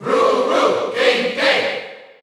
Crowd cheers (SSBU) You cannot overwrite this file.
King_K._Rool_Cheer_Dutch_SSBU.ogg